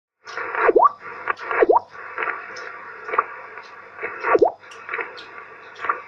На телефон записал его последние вечерние писки, работа не стабильна, частота иногда скачет хаотически, тем не менее, даже на закате пытается вещать
Пикает он как первый спутник, простенько, но все же не белый шум, а освещенность и, следовательно, напруга, определяет частоту несущей, так что и некую информацию можно от него получать.